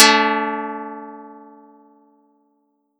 Audacity_pluck_5_13.wav